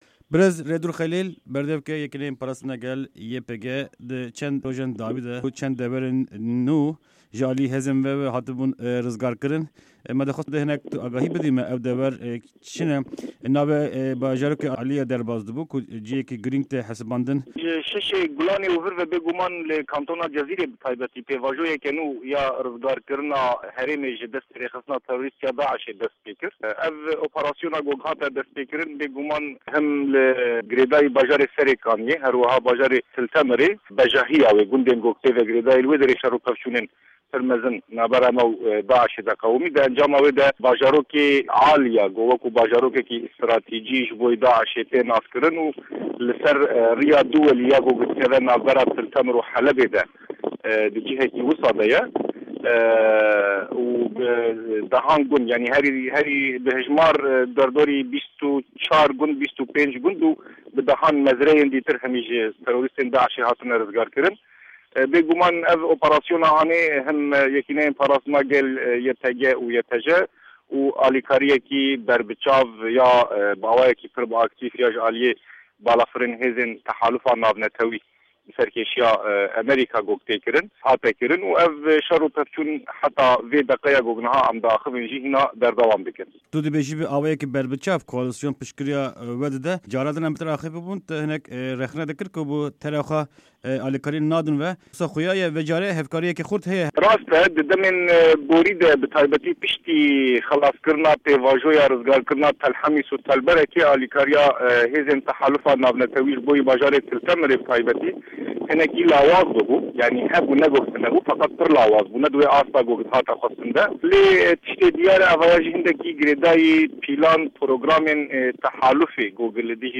Di vê hevpeyvîna taybet de